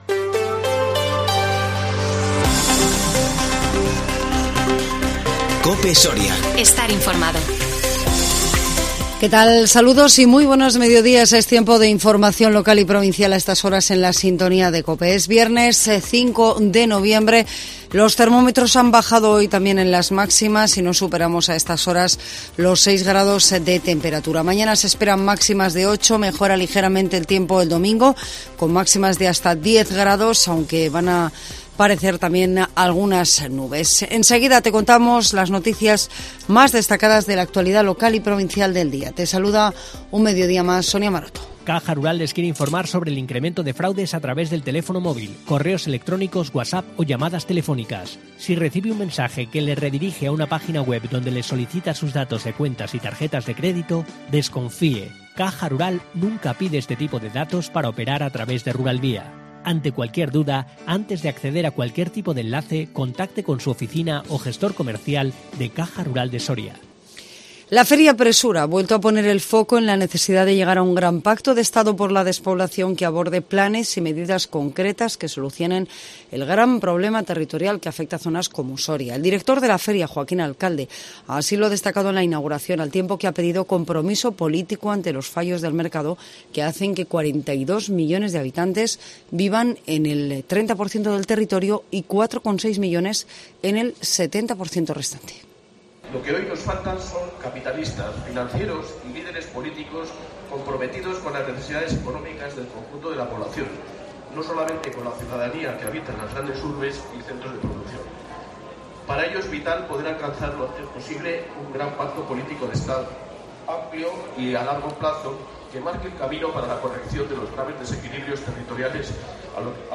INFORMATIVO MEDIODÍA 5 NOVIEMBRE 2021